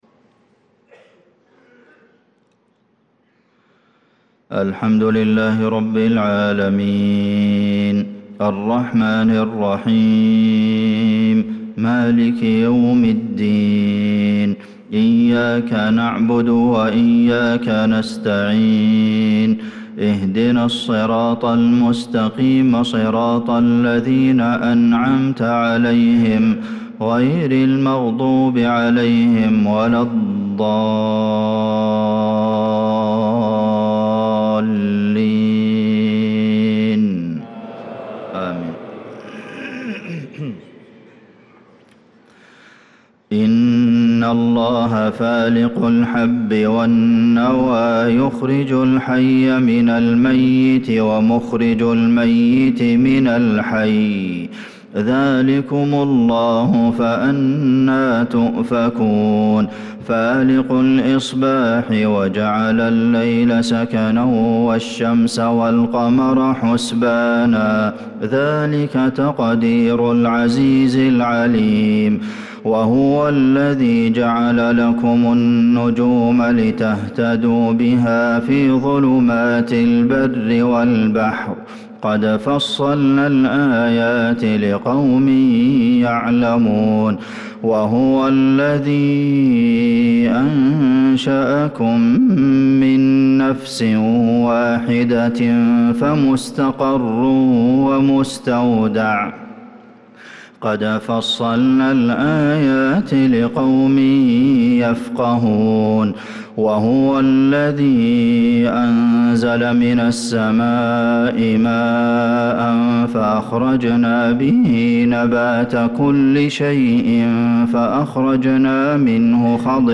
صلاة الفجر للقارئ عبدالله الجهني 30 ذو الحجة 1445 هـ
تِلَاوَات الْحَرَمَيْن .